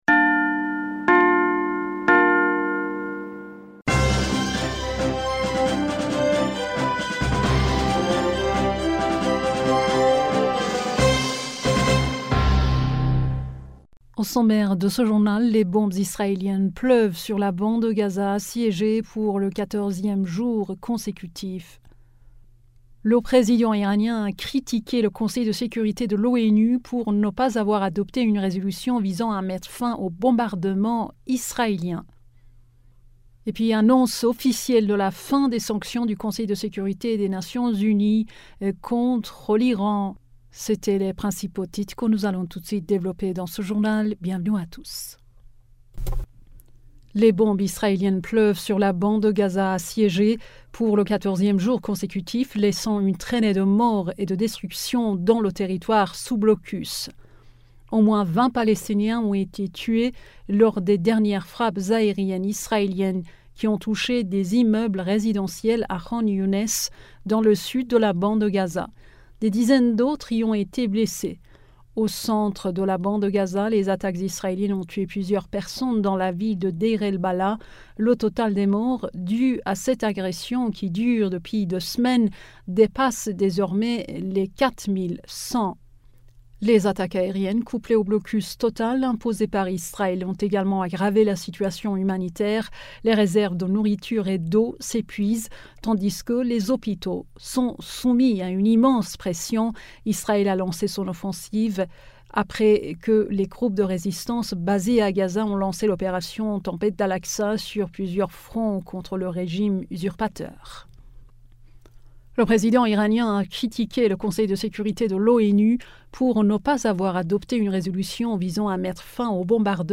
Bulletin d'information du 20 Octobre 2023